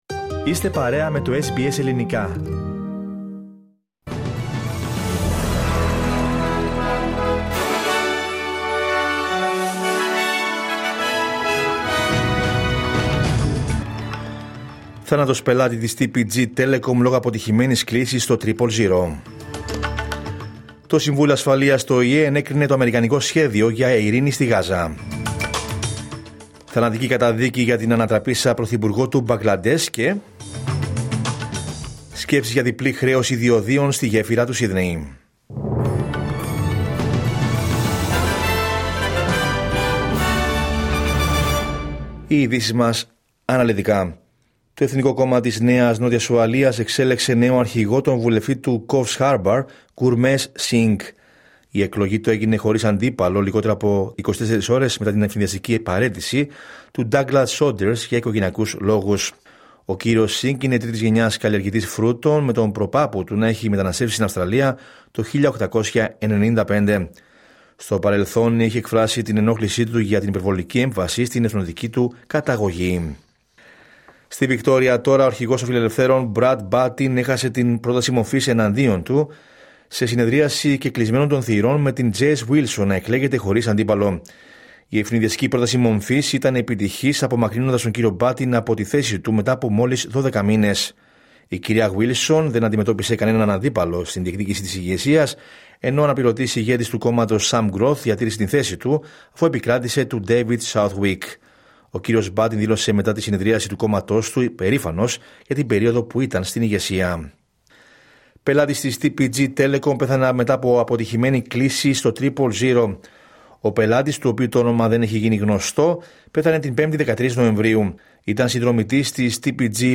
Ειδήσεις: Τρίτη 18 Νοεμβρίου 2025